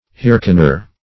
Hearkener \Heark"en*er\ (-[~e]r), n.